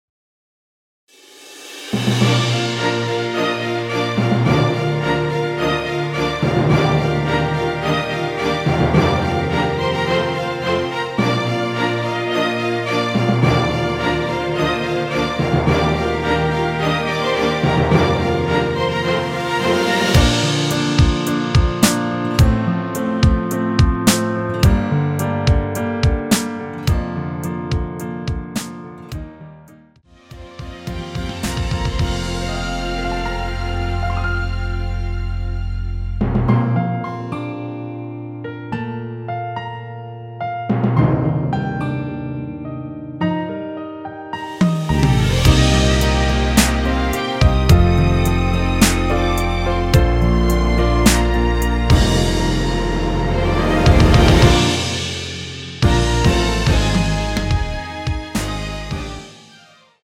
원키에서(-2)내린 MR입니다.
Bb
앞부분30초, 뒷부분30초씩 편집해서 올려 드리고 있습니다.
중간에 음이 끈어지고 다시 나오는 이유는